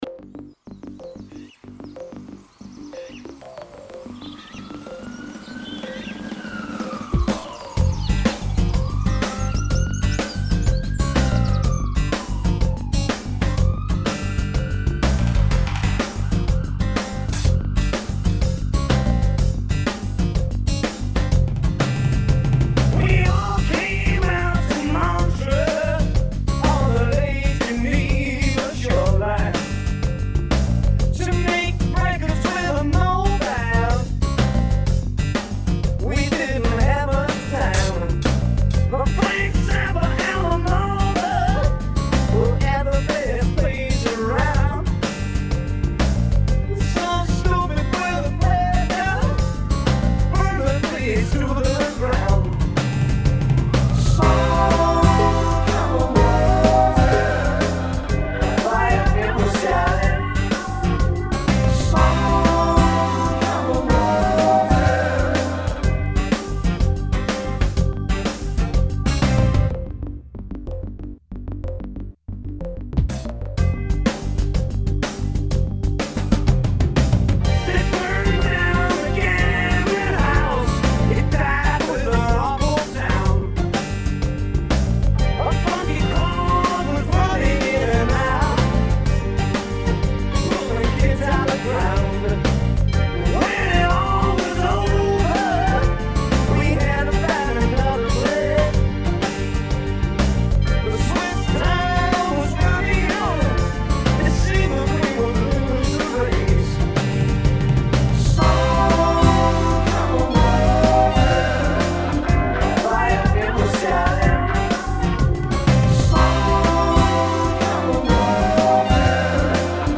guitar riff
over a 12/8 blues rock rhythm.